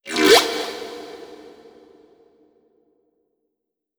khloCritter_Female01-Verb.wav